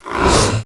Heroes3_-_Vampire_-_AttackSound.ogg